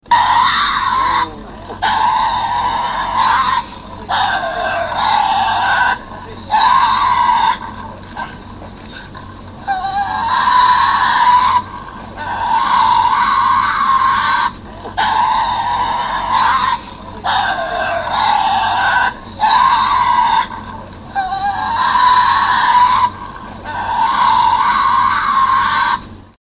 tasmanian-devil-sound_14226.mp3